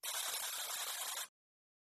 buzzer.mp3